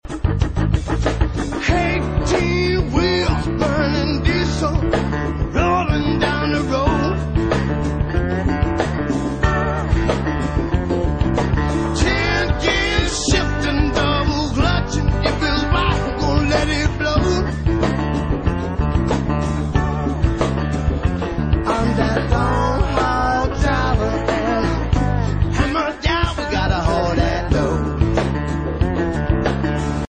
Category Country